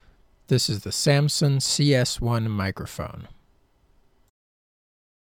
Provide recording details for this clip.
Microphone-Test-Samples-Samson-CS1.mp3